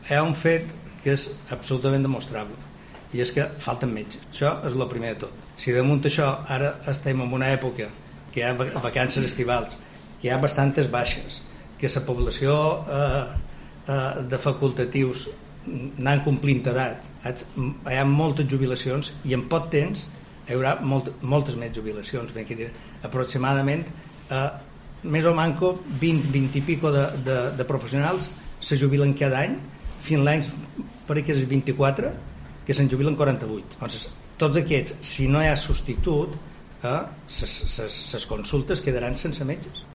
CORTE DE VOZ SIMEBAL